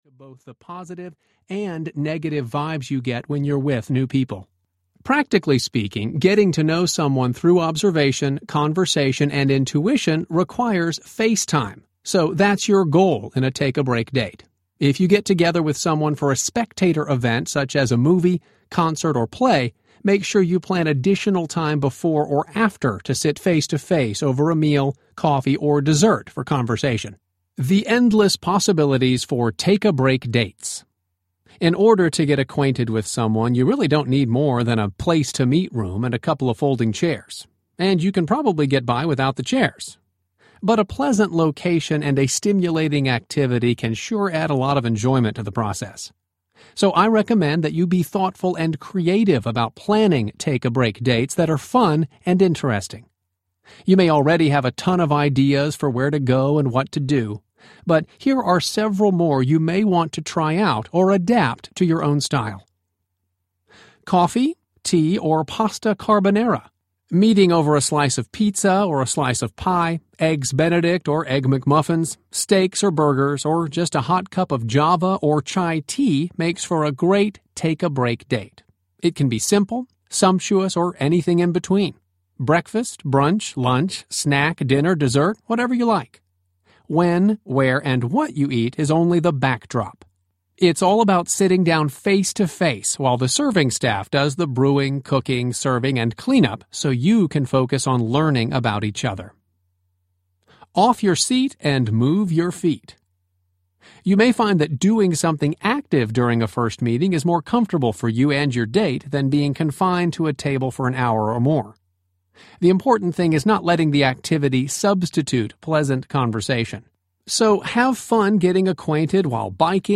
Is This the One? Audiobook
Narrator
5.5 Hrs. – Unabridged